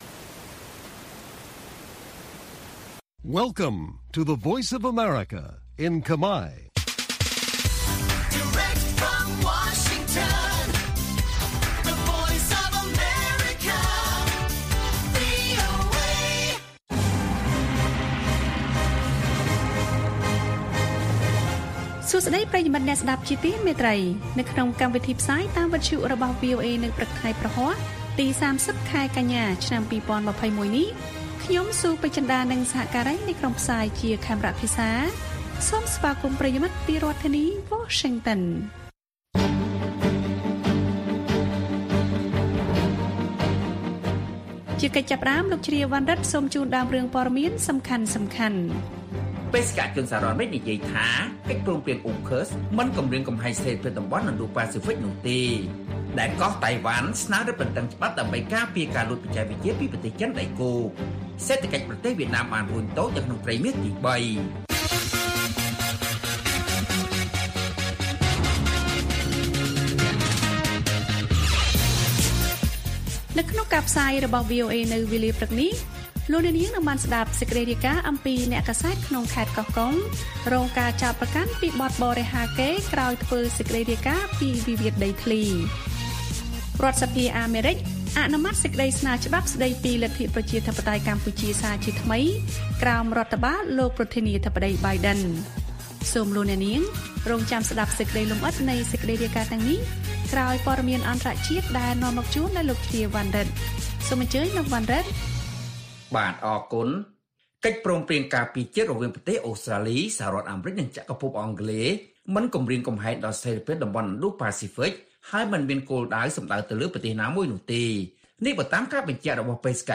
ព័ត៌មានពេលព្រឹក៖ ៣០ កញ្ញា ២០២១